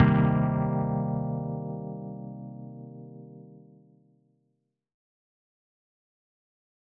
004_Min7.L.wav